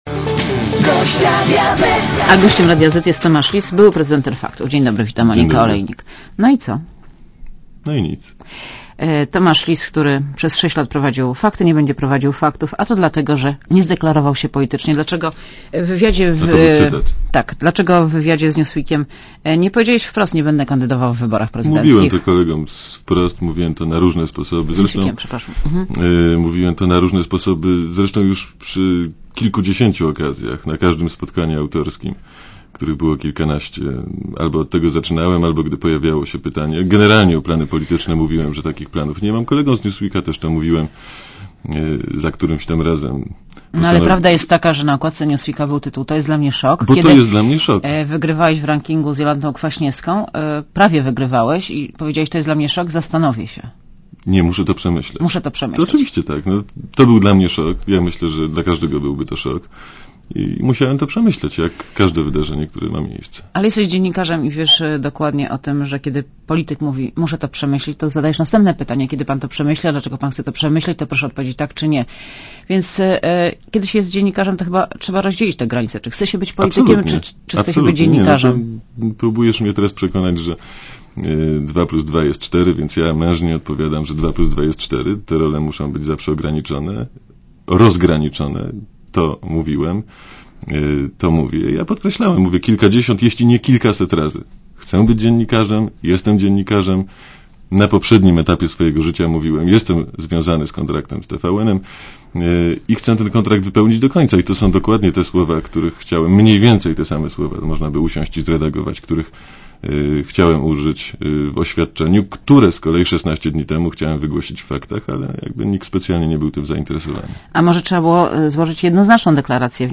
Gościem Radia Zet jest Tomasz Lis, były prezenter „Faktów”.